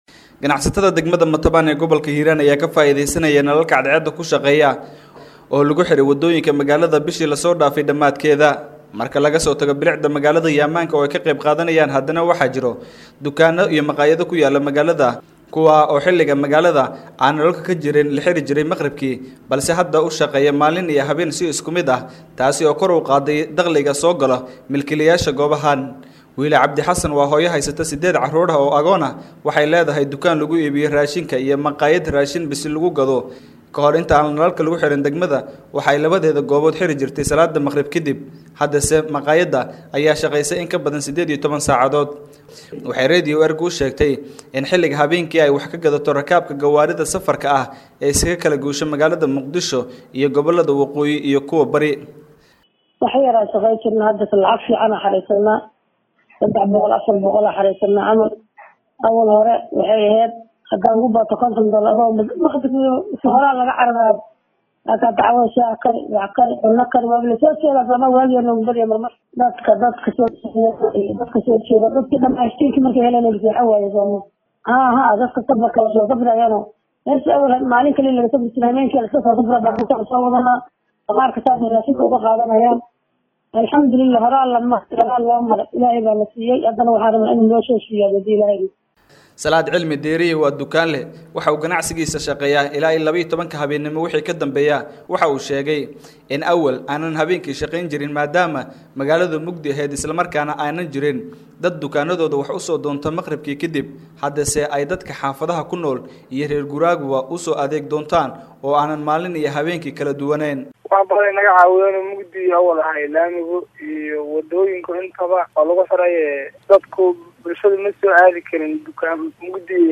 Warbixinta-Matabaan-.mp3